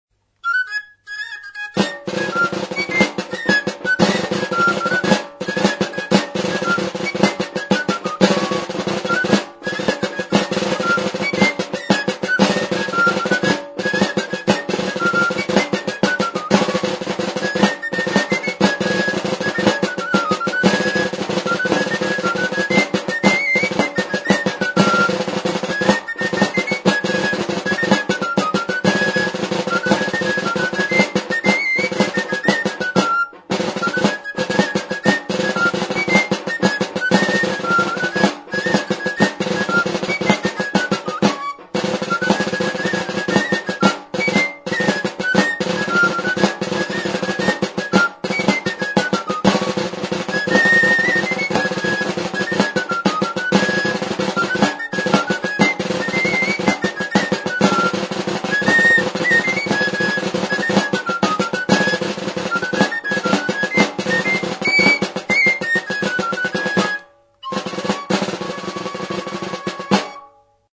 Membranophones -> Beaten -> Stick-beaten drums
Arizkun, 1984.